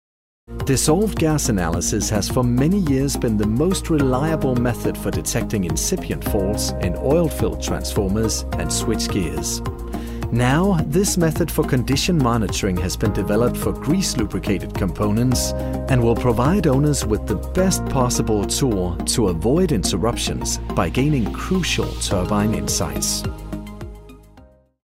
Male
Approachable, Character, Conversational, Corporate, Energetic, Engaging, Friendly, Gravitas, Natural, Reassuring, Sarcastic, Soft, Upbeat, Versatile, Warm
Microphone: Neumann u87